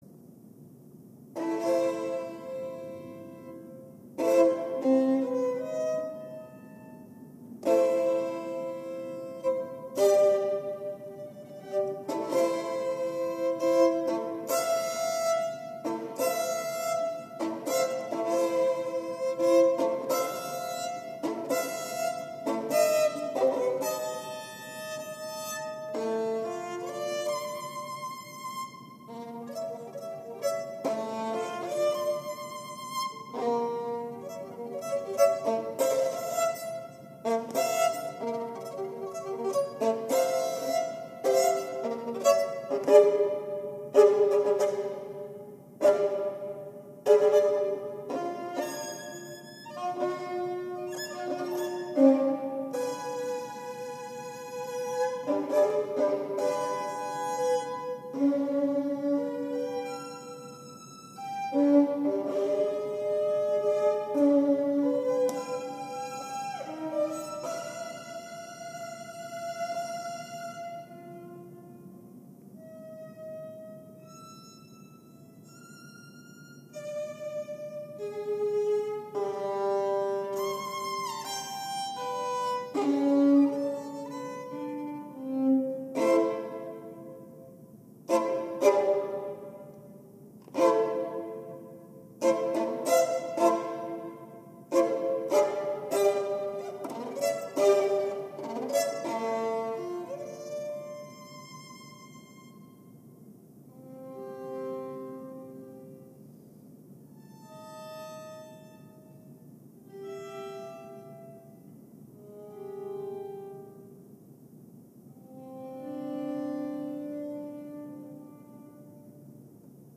Live in Baltimore
4th April 2012 Old Episcopalian Church